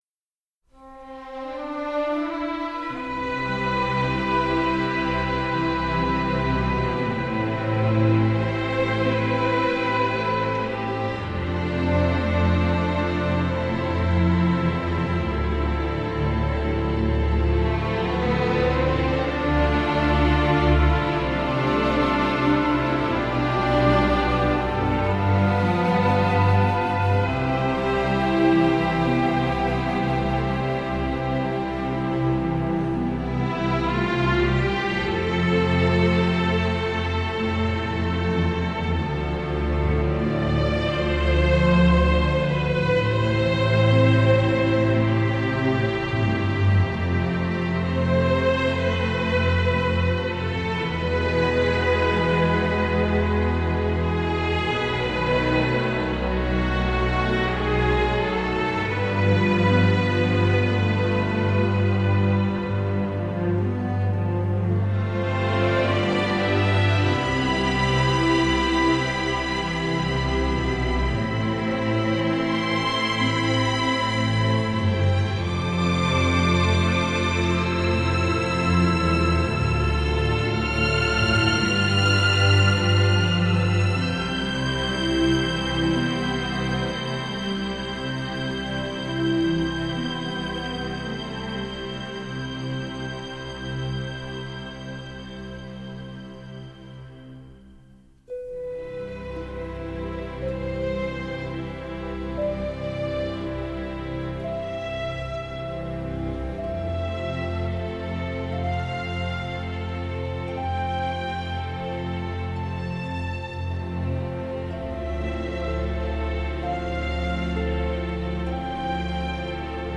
C’est donc entêtant et beau, ou… répétitif et kitsch.